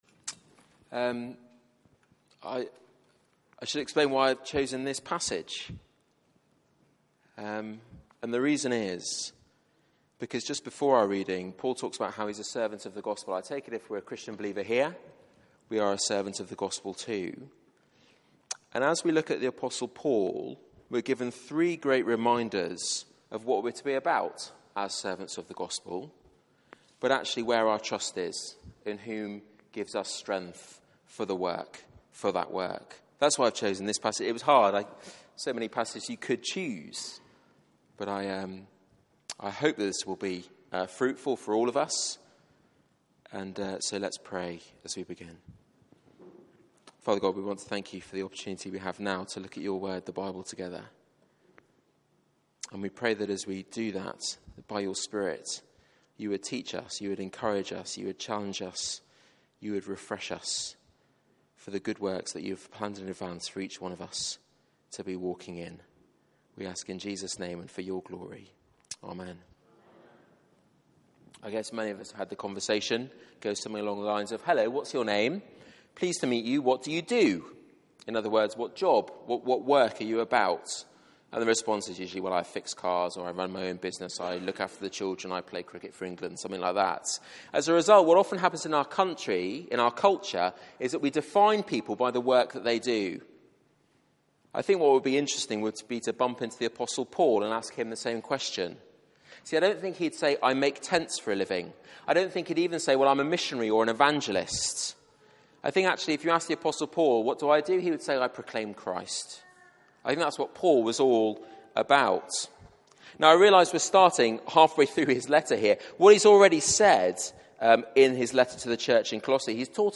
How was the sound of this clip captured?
Media for 4pm Service on Sun 19th Jul 2015 16:00 Speaker